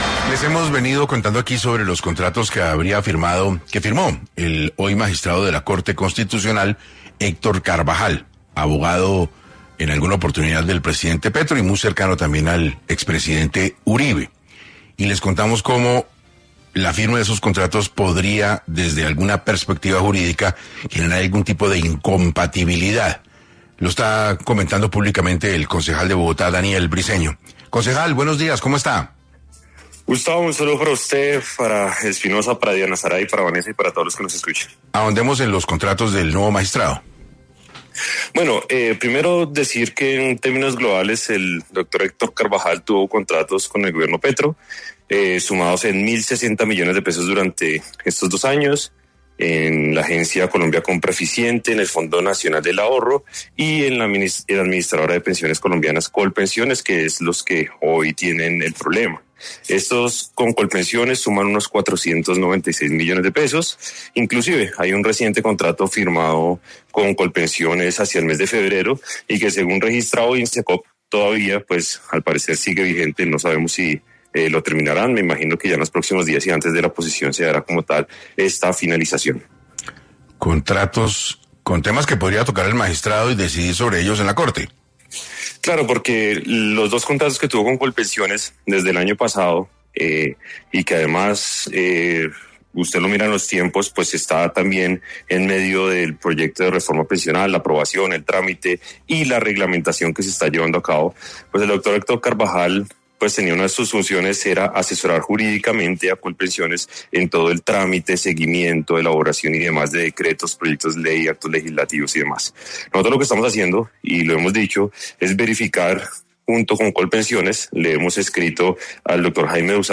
Otro de los temas tocados en entrevista con la mesa de trabajo de 6AM fue las columnas del metro, que han sido controversiales por estos días.